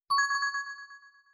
success-notification.wav